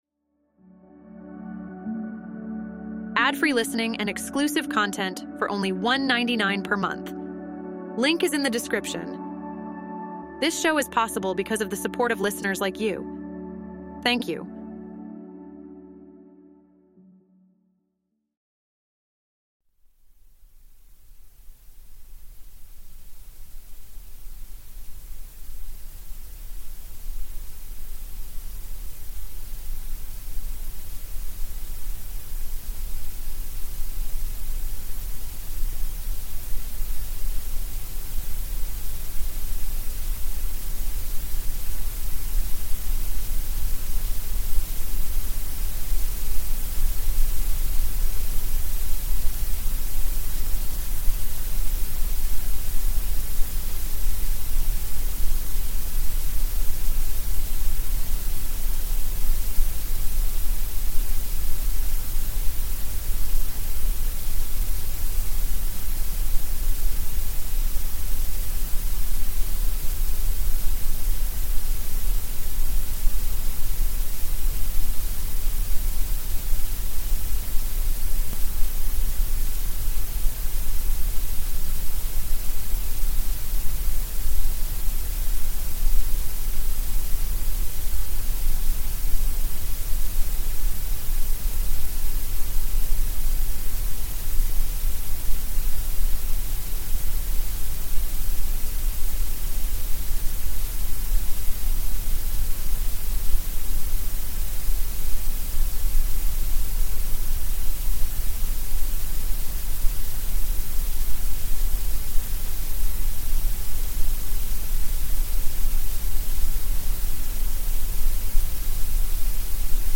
Each episode of "White Noise Wednesdays" features a soothing blend of gentle sounds, including the soothing hum of white noise and calming nature sounds.